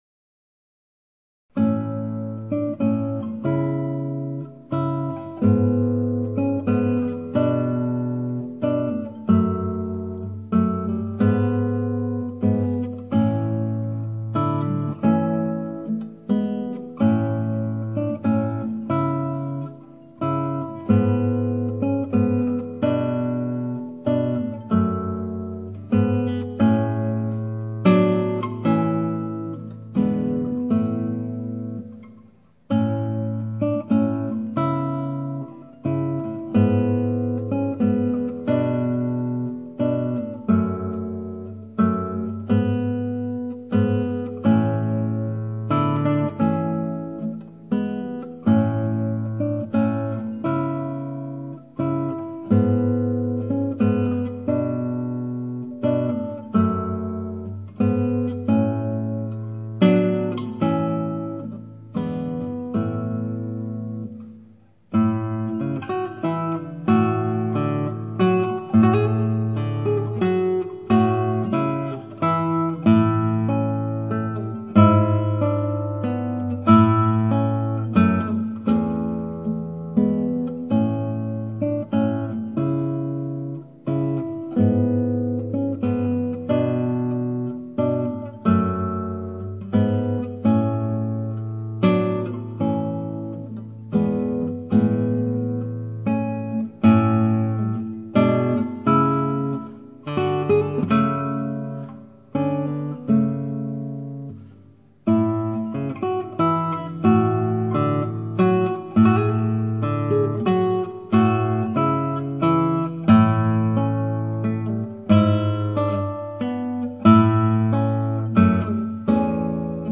(アマチュアのクラシックギター演奏です [Guitar amatuer play] )
ギターなので、実音はピアノより1オクダーブ低くなっています。
演奏は譜を作って3日ぐらいでいきなり録音しましたのでスムーズさがなく重々しい感じになってしまいました。